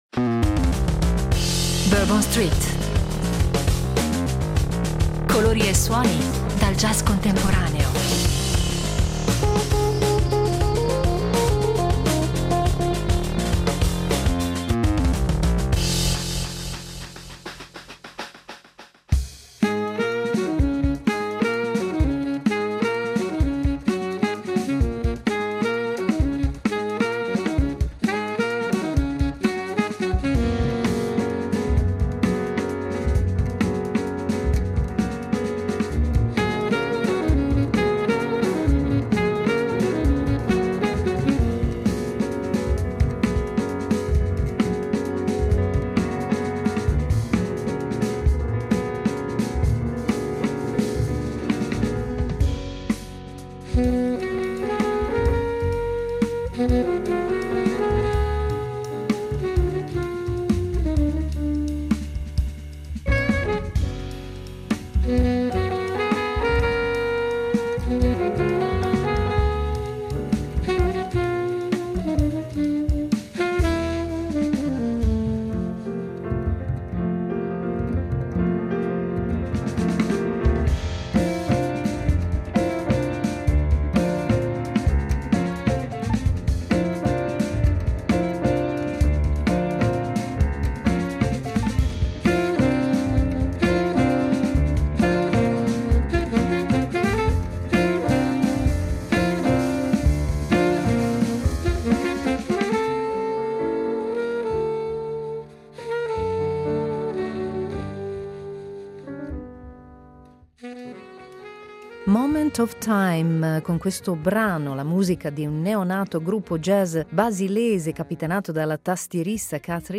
Album sensibile, malinconico e brillante allo stesso tempo.